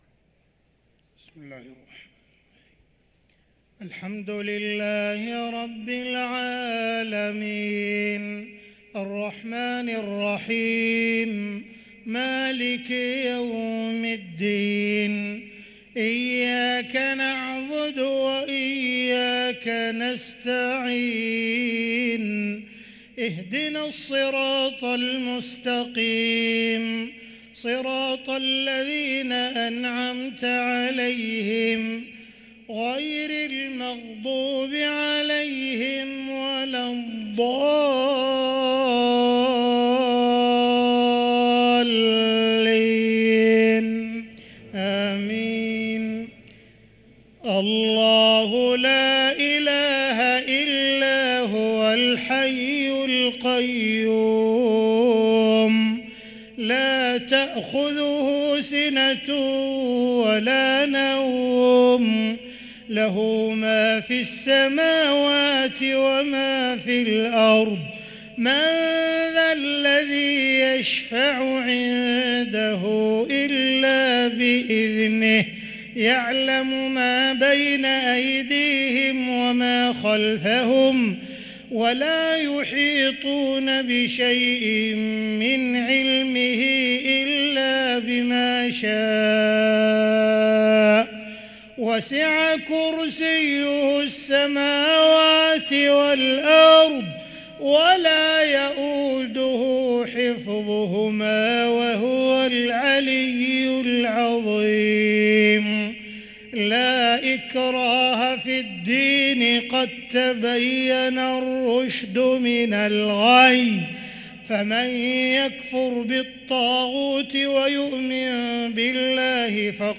مغرب الثلاثاء 13 رمضان 1444هـ من سورة البقرة | Maghreb prayer from Surah Al-Baqarah 4-4-2023 > 1444 🕋 > الفروض - تلاوات الحرمين